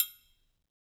Triangle3-HitFM_v2_rr1_Sum.wav